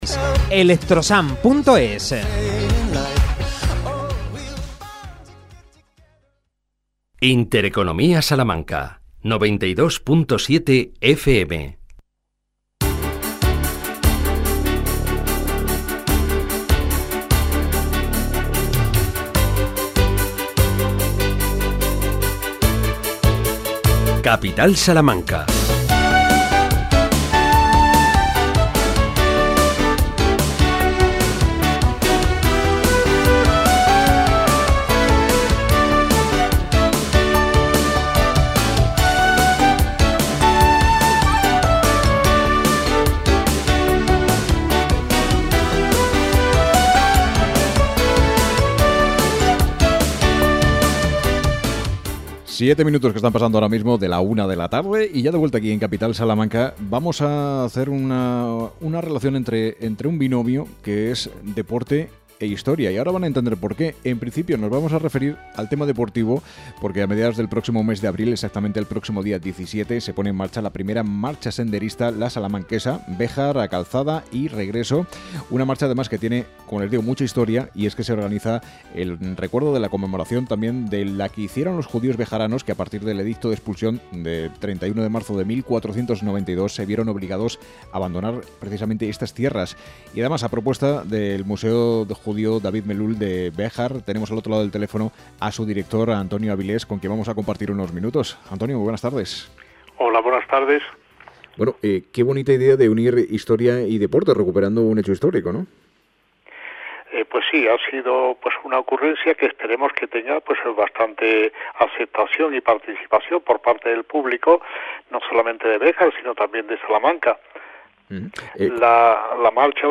Entrevista en Intereconomía Salamanca